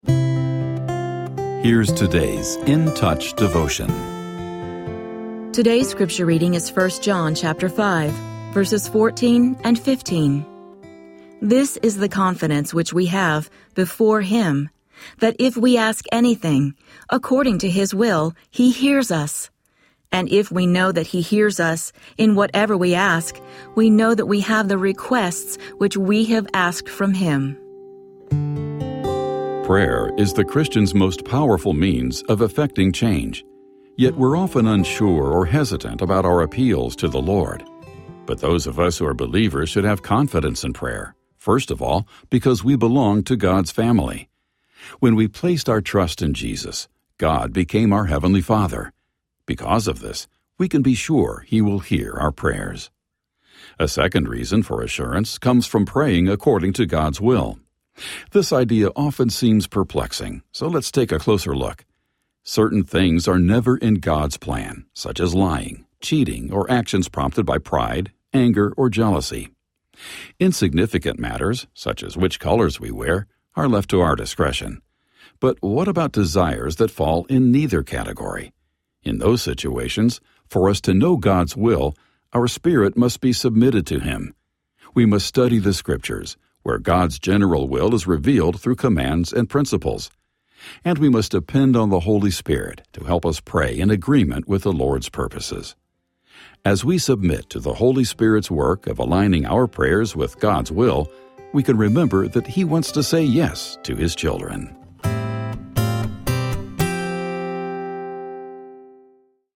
Daily audio devotional from Charles Stanley’s In Touch Ministries.